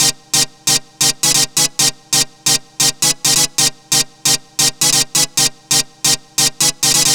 Lead 134-BPM C.wav